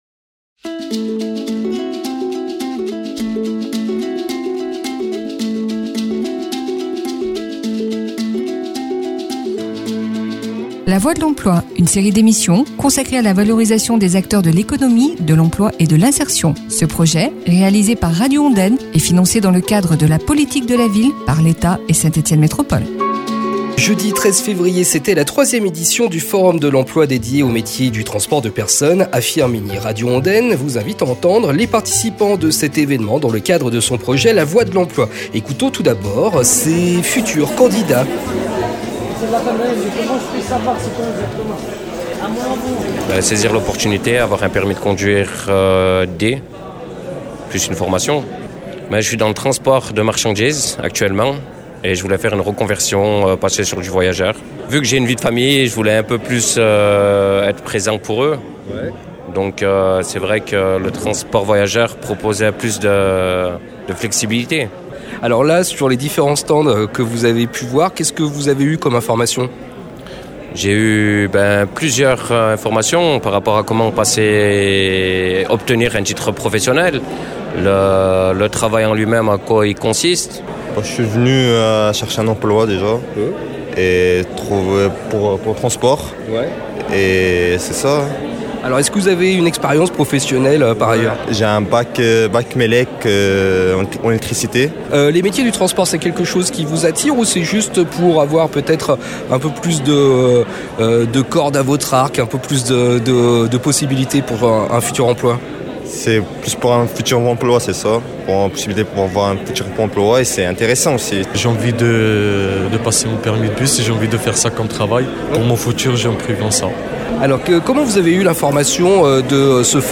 LA VOIX DE L’EMPLOI, Forum de l’emploi dédié aux transports de personnes à Firminy, reportage. – Radio Ondaine 90.9 FM
Ce jeudi 13 Février, la ville de Firminy et ses partenaires organisaient la 3ème édition du Forum de l’emploi dédié aux métiers du transport. Radio Ondaine vous propose d’écouter, aujourd’hui, à 11h30, un reportage sur cet évènement avec les différents acteurs de ces rencontres.